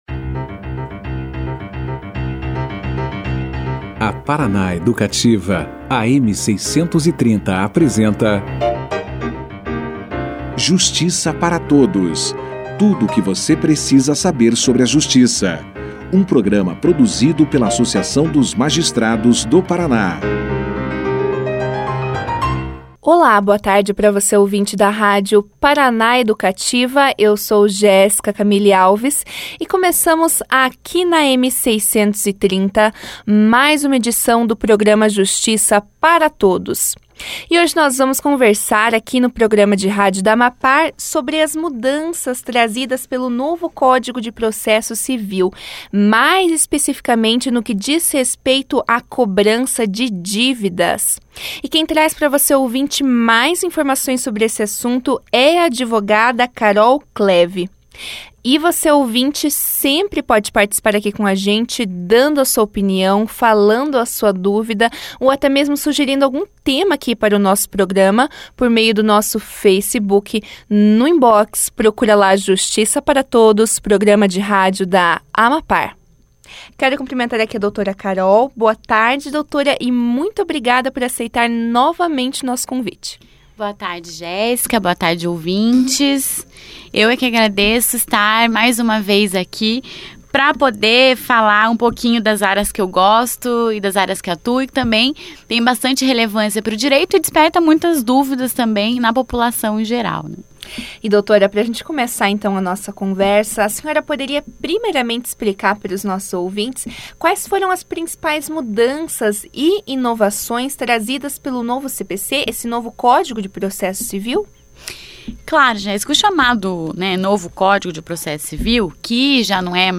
Logo no início a entrevista, a convidada explicou algumas das principais mudanças e falou como os operadores do direito têm se adaptado a elas.